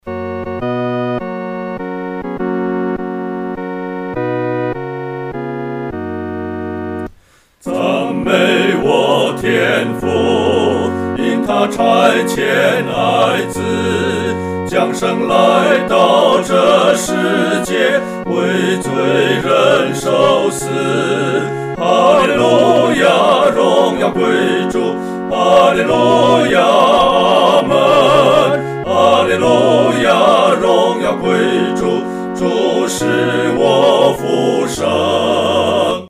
合唱（四声部）